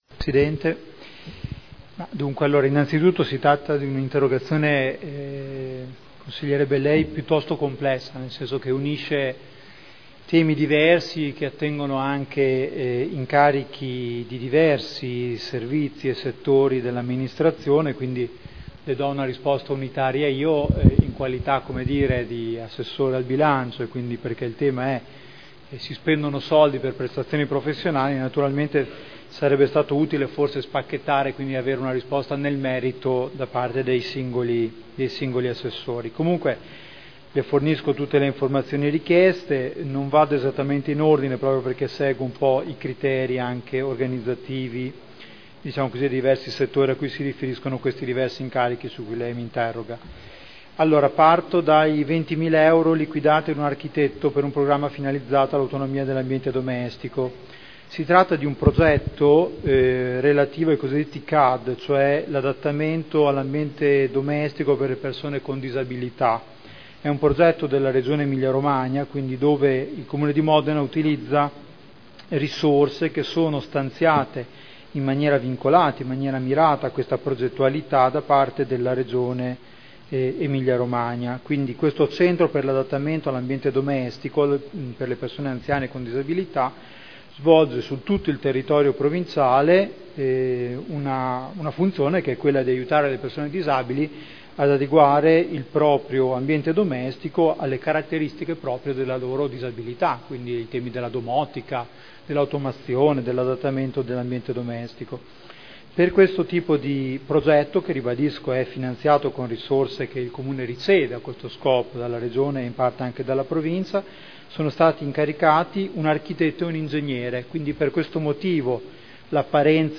Giuseppe Boschini — Sito Audio Consiglio Comunale